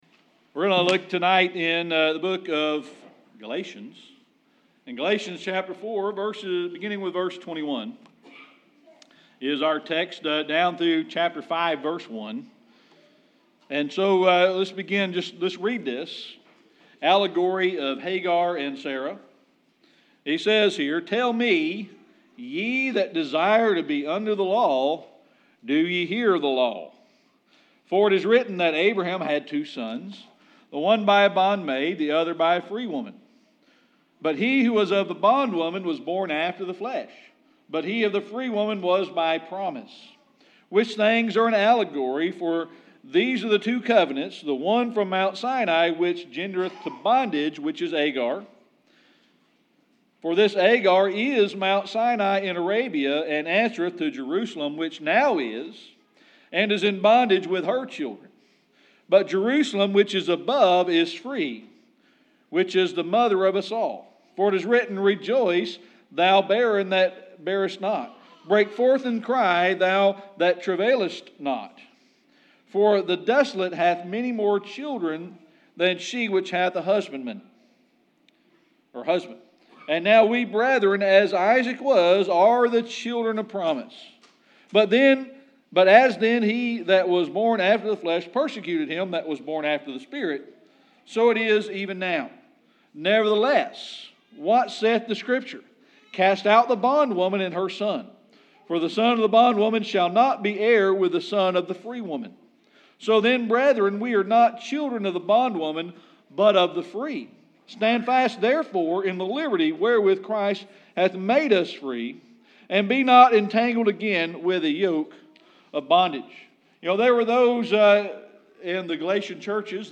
Galatians 4:21-5:1 Service Type: Sunday Evening Worship We're going to look tonight in the book of Galatians.